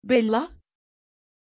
Pronuncia di Bella
pronunciation-bella-tr.mp3